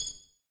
menuclick.ogg